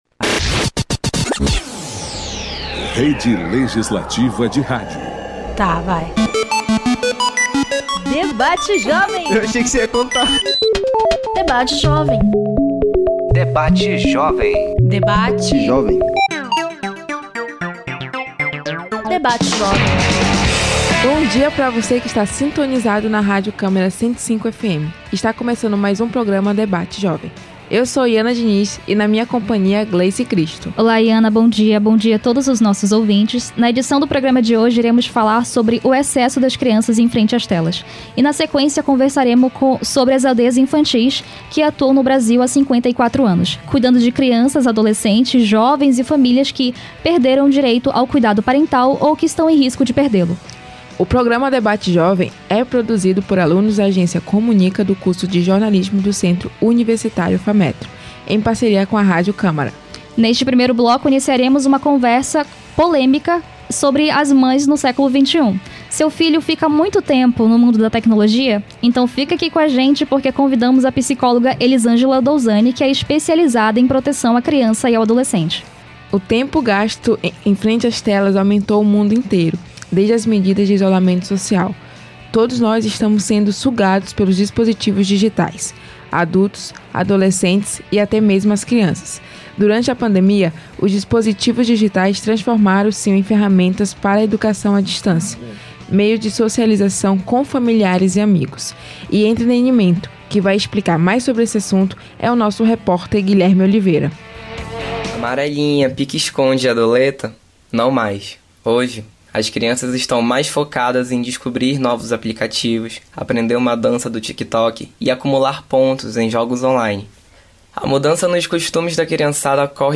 Confira no programa Debate Jovem: Excesso das crianças em frente as telas e o trabalho das Aldeias Infantis. A primeira entrevista
Debate Jovem é um programa realizado pela Agência Experimental de Comunicação do curso de Jornalismo do Centro Universitário Fametro em parceria com Radio Câmara a Rádio Cidadã de Manaus, 105,5 FM.